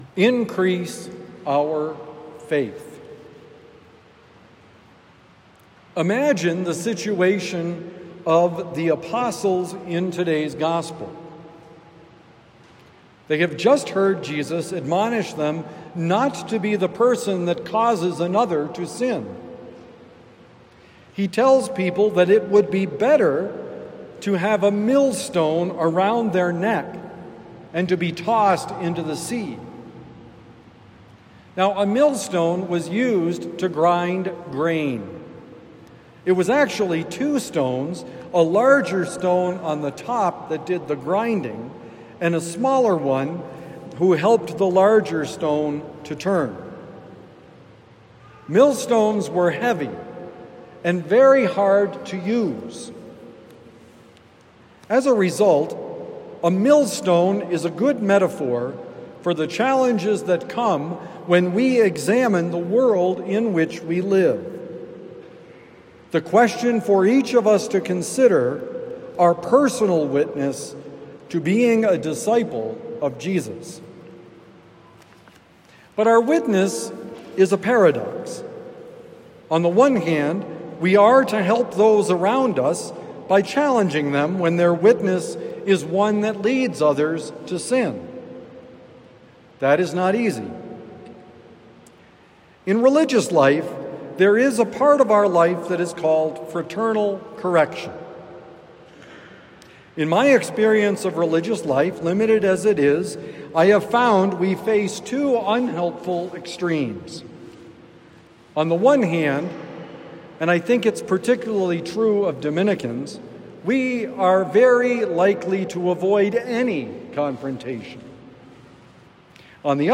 Does God listen? Homily for Sunday, October 5, 2025